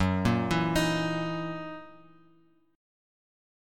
Gb13 chord